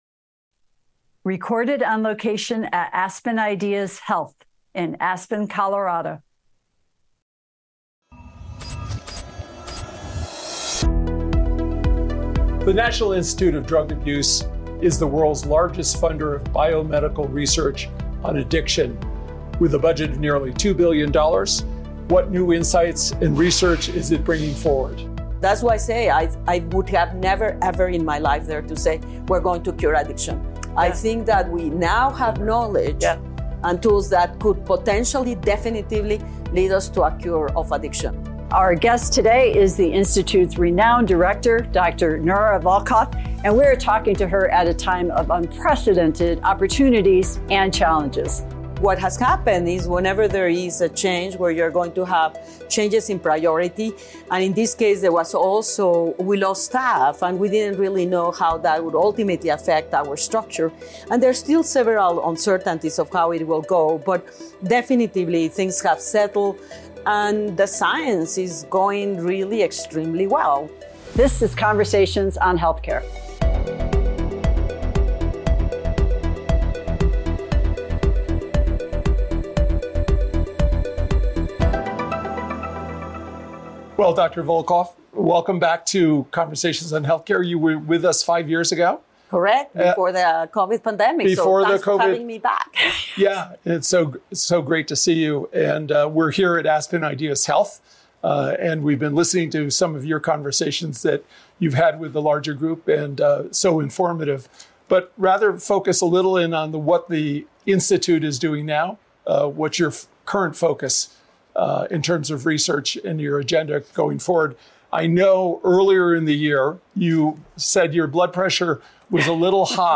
— Dr. Nora Volkow, Director, National Institute on Drug Abuse
Dr. Volkow shared her attention-getting belief with “Conversations on Health” at Aspen Ideas: Health.